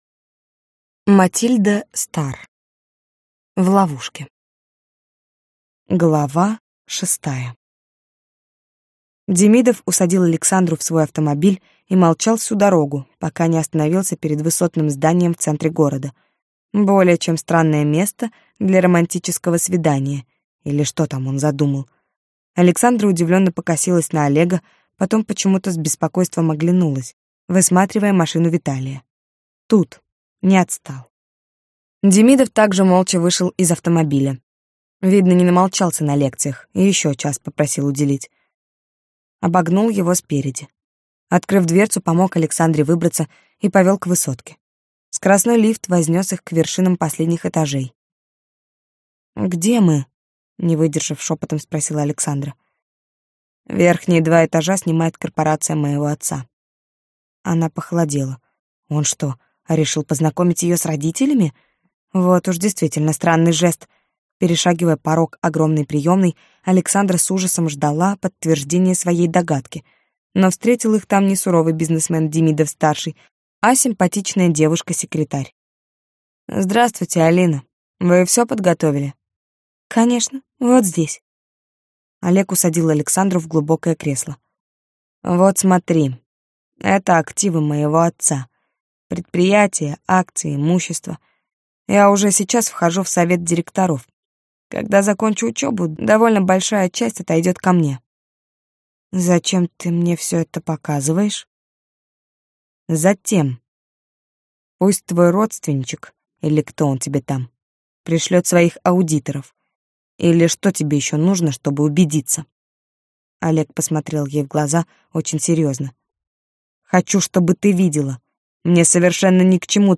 Аудиокнига В ловушке - купить, скачать и слушать онлайн | КнигоПоиск